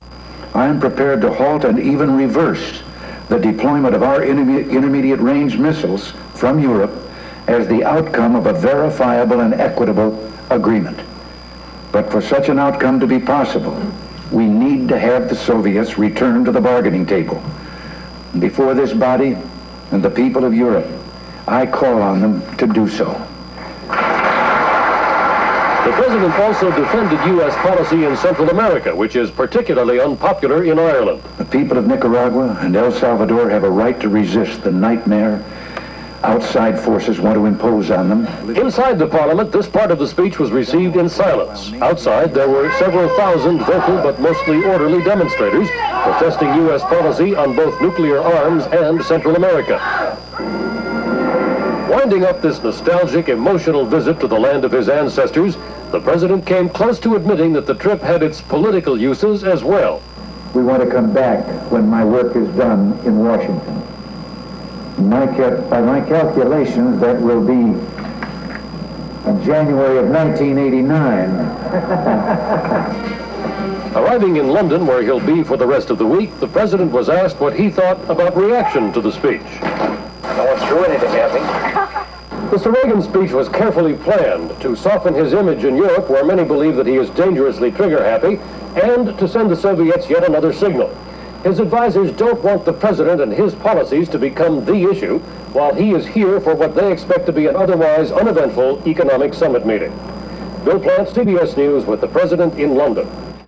U.S. President Ronald Reagan speaks before the Irish parliament